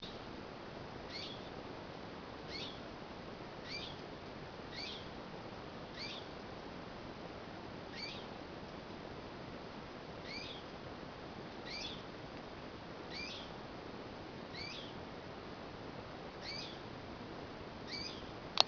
Richiamo (ornitico?) notturno da identificare...
Richiamo registrato in foresta del Cansiglio alle 6.00 di mattina... di sicuro non è un ghiro: è un uccello? e che uccello allora????